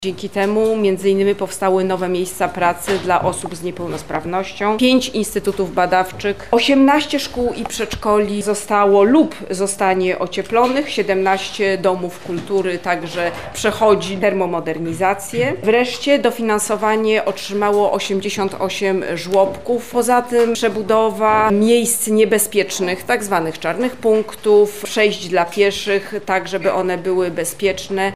To jest 86 podmiotów ekonomii społecznej, które już dostały wsparcie – mówi Minister Katarzyna Pełczyńska-Nałęcz: